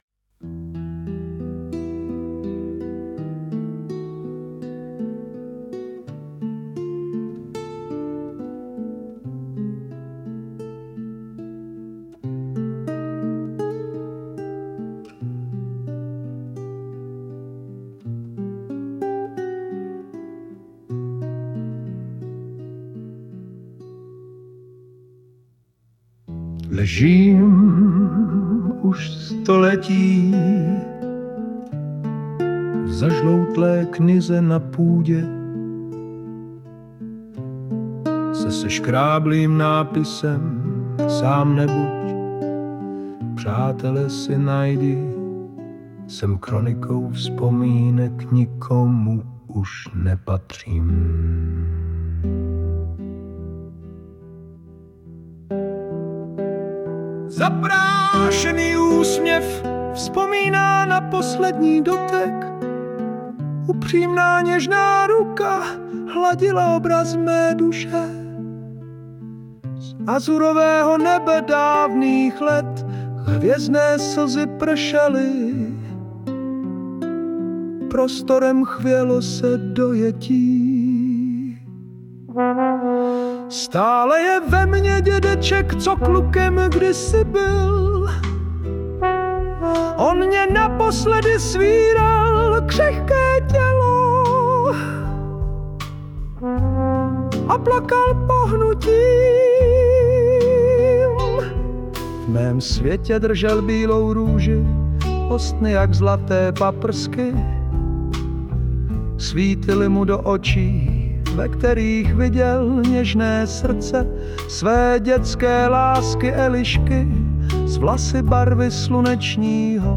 Kategorie: Lyrická a Elegická poezie
AI generovaná hudba a zpěv: Ano